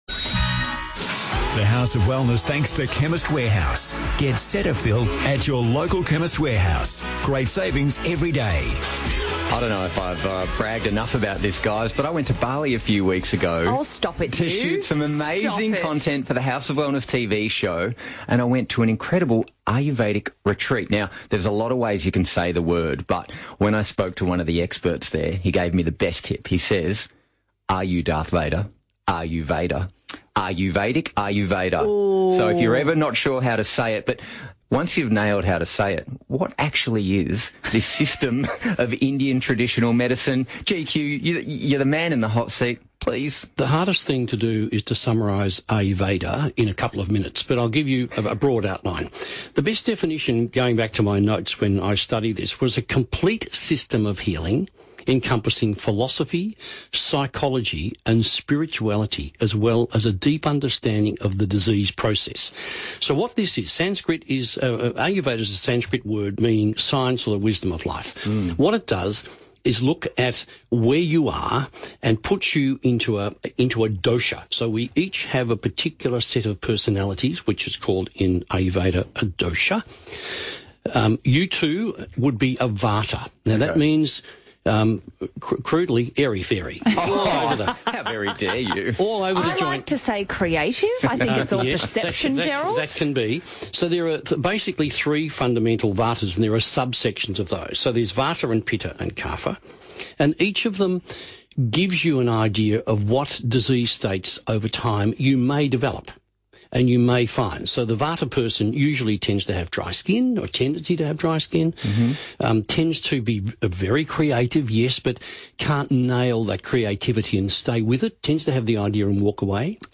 Listen as The House of Wellness Radio team discusses the ancient practice of Ayurvedic medicine: